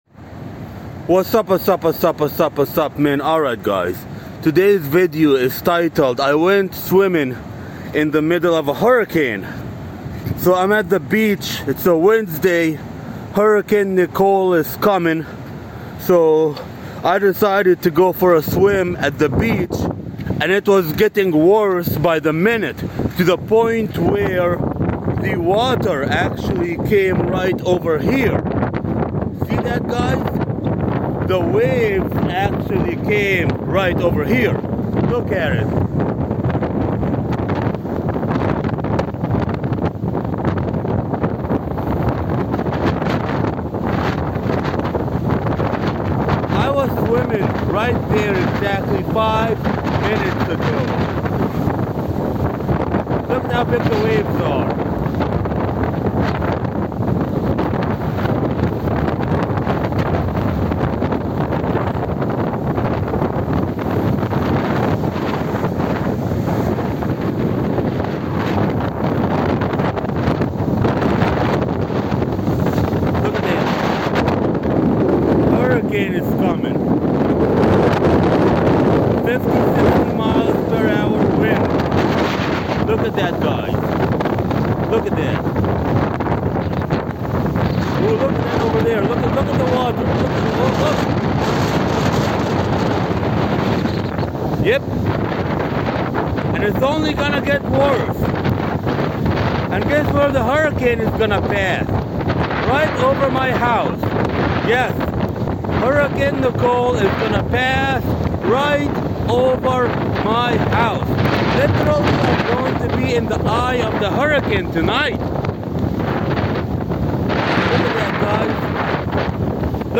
It's a bit windy - but hey...
A bit of wooly sock over your microphone - kills the wind noise.
can't hear shit due to the wind, but have fun!